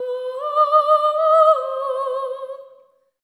LEGATO 06 -R.wav